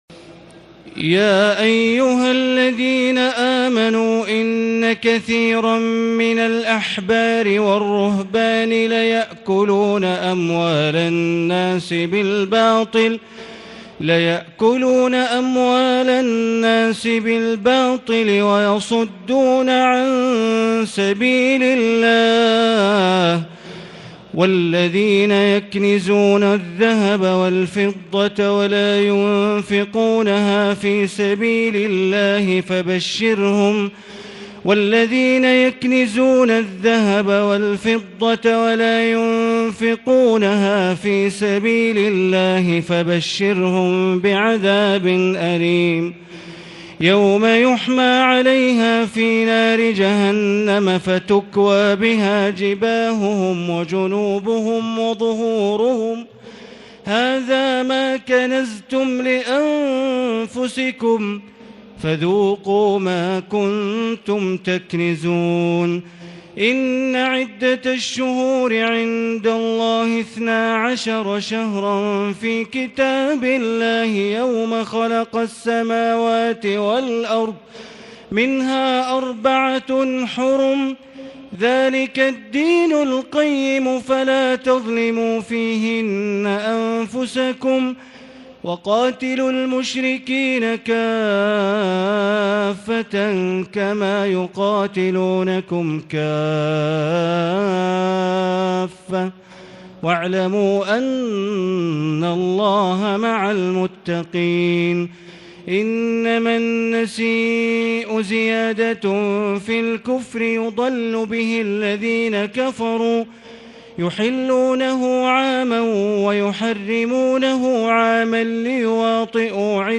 تراويح الليلة التاسعة رمضان 1440هـ من سورة التوبة (34-96) Taraweeh 9 st night Ramadan 1440H from Surah At-Tawba > تراويح الحرم المكي عام 1440 🕋 > التراويح - تلاوات الحرمين